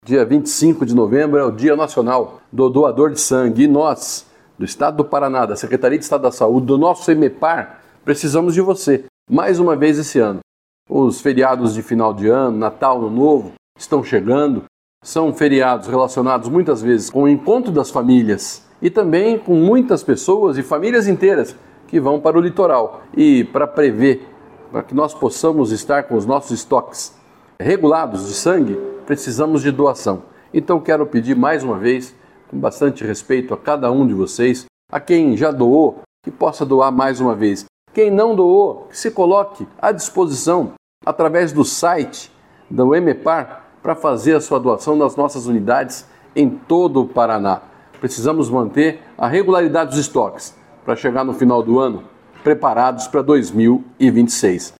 Sonora do secretário da Saúde, Beto Preto, sobre a importância da doação de sangue | Governo do Estado do Paraná